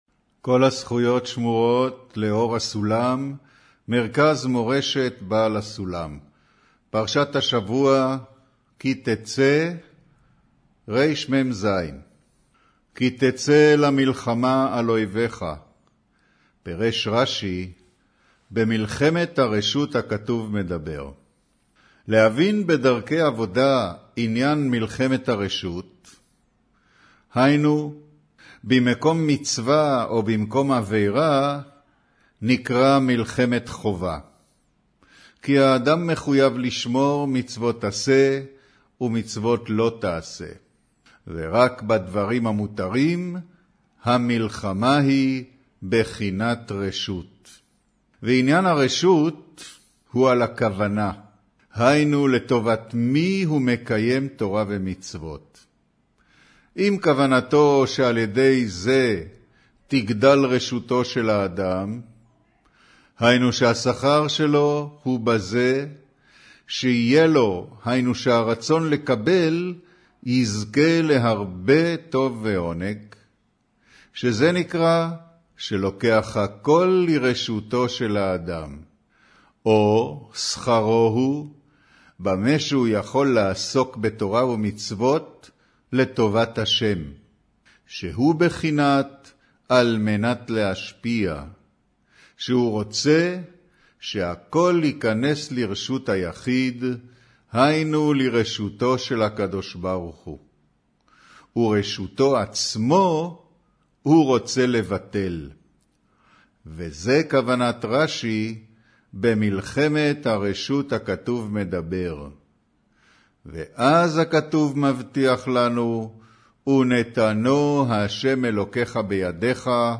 אודיו - קריינות פרשת כי תצא, מאמר כי תצא למלחמה על אויביך וגו'